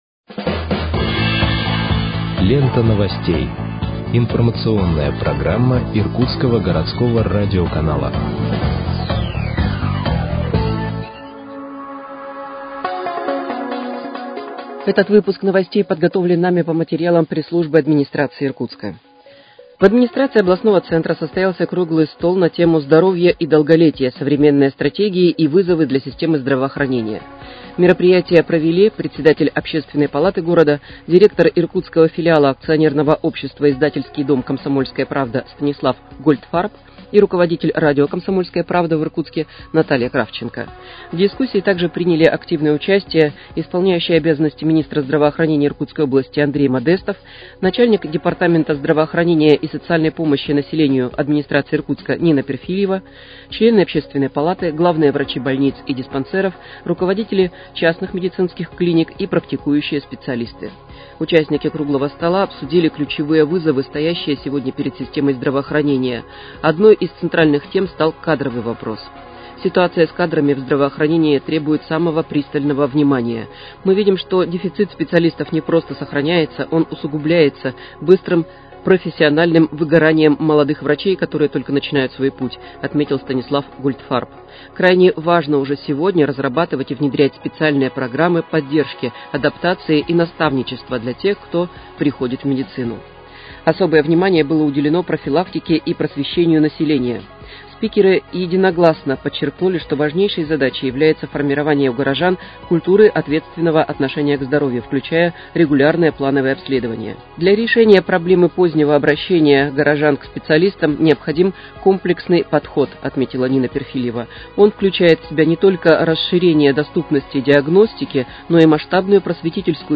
Выпуск новостей в подкастах газеты «Иркутск» от 10.12.2025 № 2